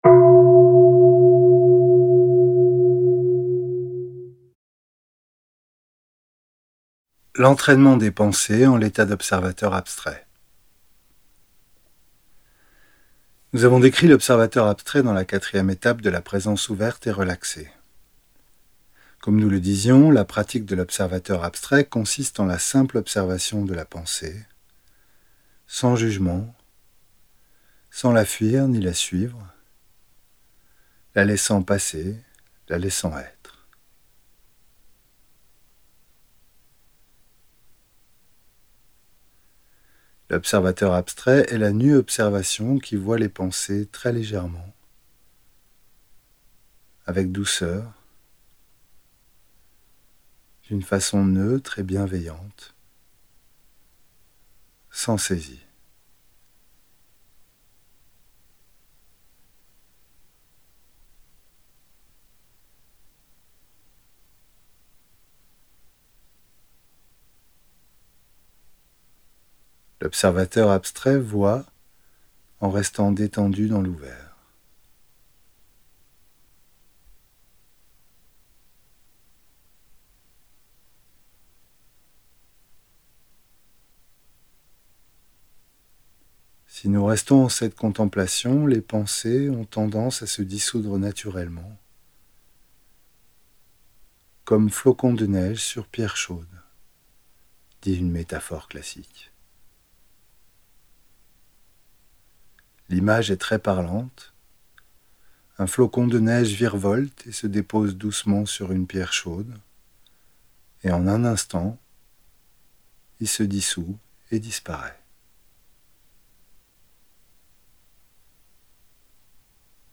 Audio homme